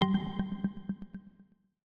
UIClick_Long Modern Echo 02.wav